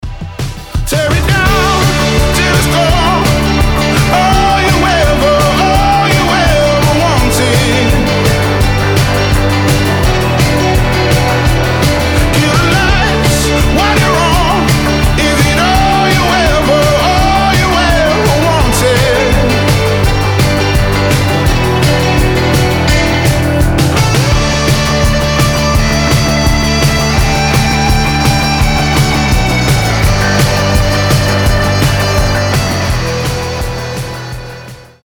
мужской голос
ритмичные
alternative
indie rock
барабаны